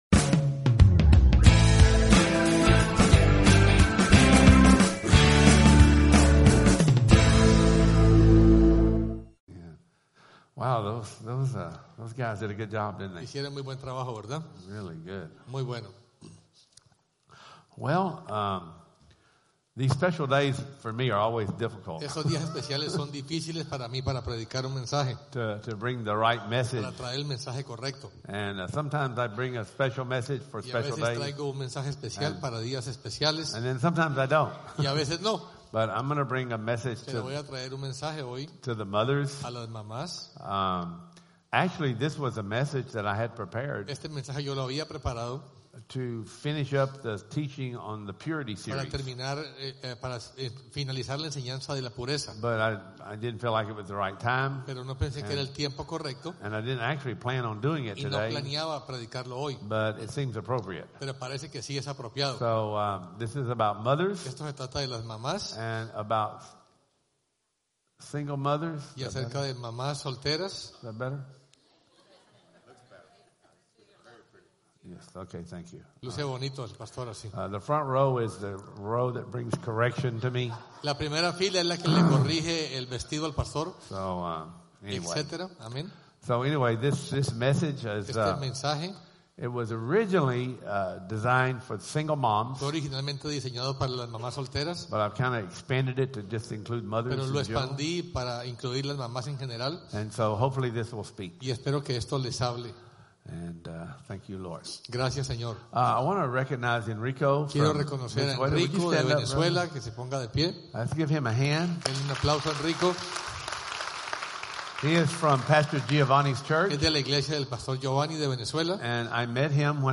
Sunday 2016 Services Service Type: Sunday Service « Leviathan